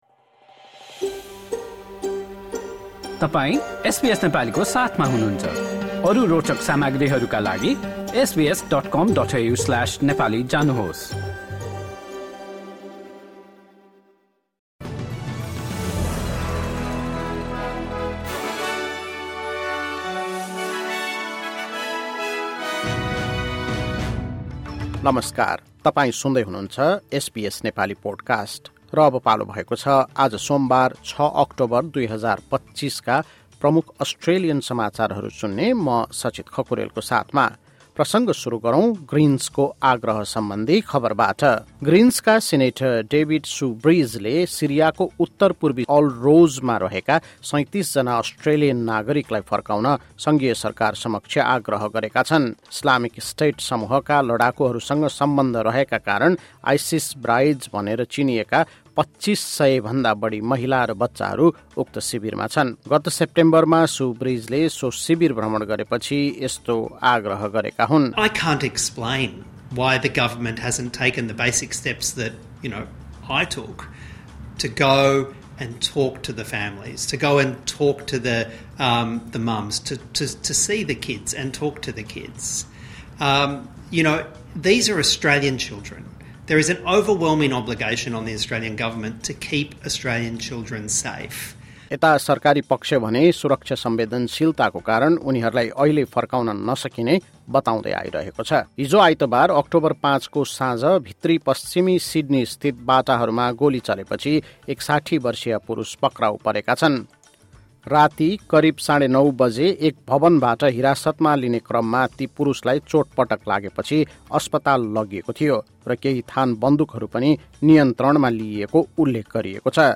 एसबीएस नेपाली प्रमुख अस्ट्रेलियन समाचार: सोमवार, ६ अक्टोबर २०२५